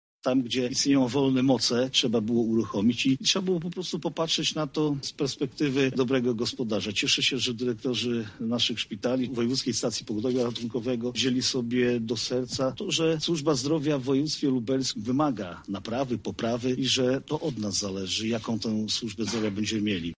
Nasze działania oszczędnościowo – restrukturyzacyjne polegają na tym że szukamy oszczędności, ale nie kosztem pacjentów – mówi Jarosław Stawiarski, Marszałek Województwa Lubelskiego.